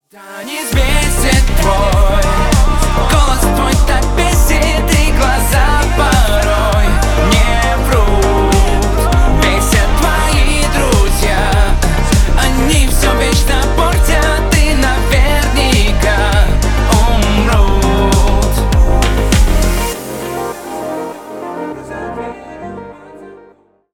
Поп Музыка
тихие